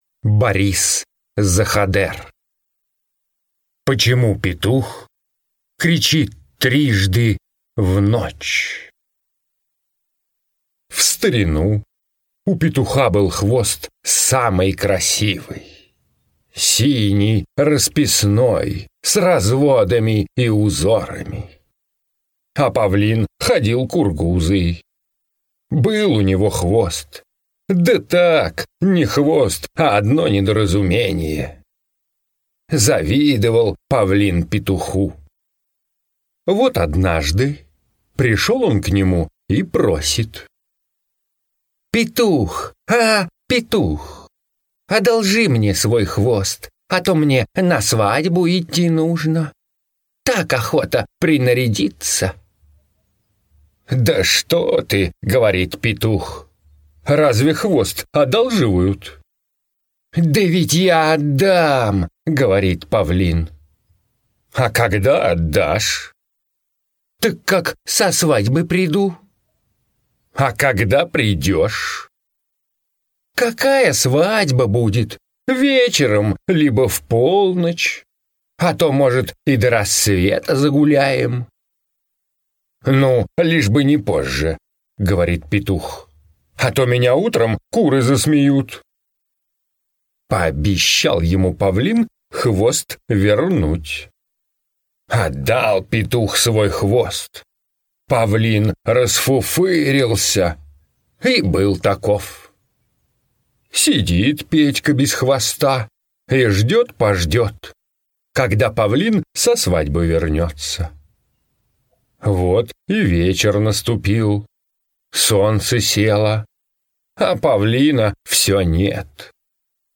На данной странице вы можете слушать онлайн бесплатно и скачать аудиокнигу "Почему петух кричит трижды в ночь" писателя Борис Заходер.